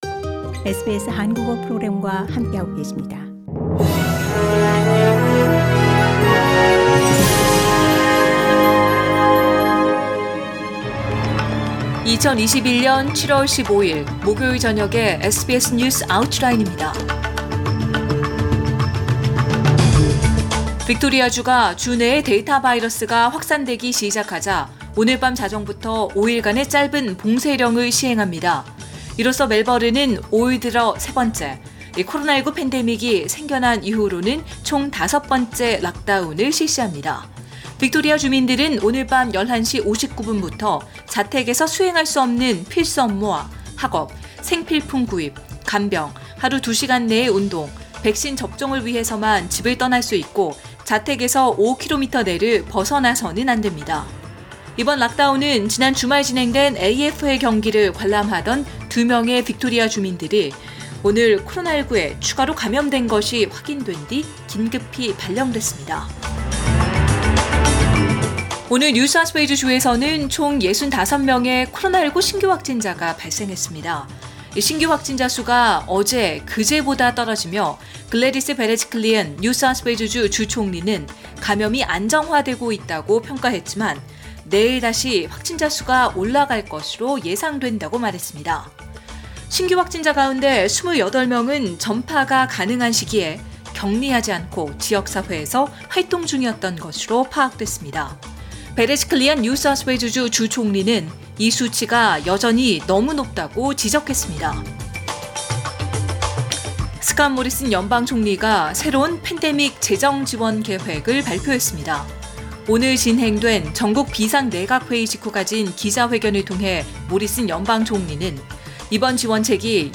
SBS News Outlines…2021년 7월 15일 저녁 주요 뉴스